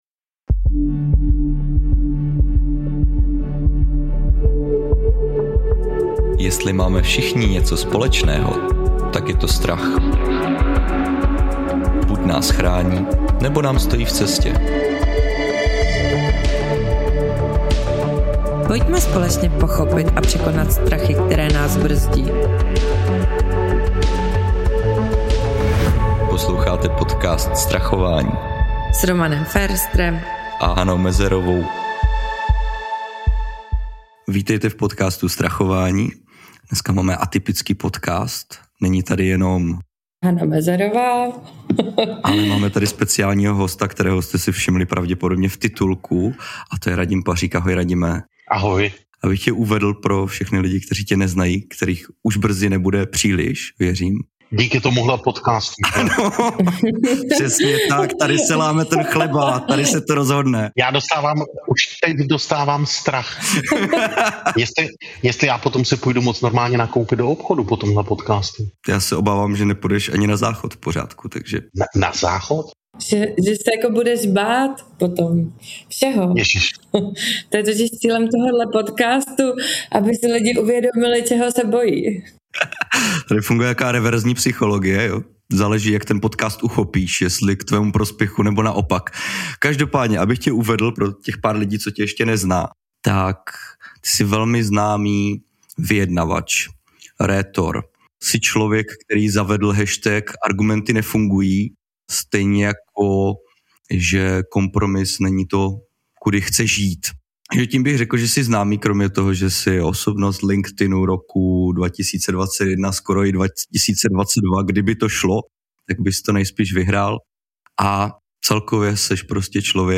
I takhle může vypadat strach z vyjednávání. Abyste se ho nebáli, pozvali jsme do tohoto dílu podcastu profesionálního vyjednavače, díky kterému se dozvíte, jak se k vyjednávání postavit beze strachu.